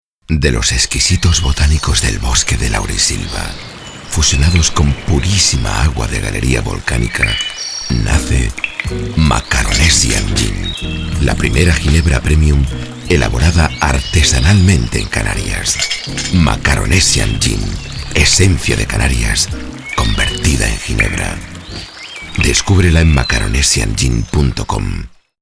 VOZ GRAVE, BAJO.
kastilisch
Sprechprobe: Werbung (Muttersprache):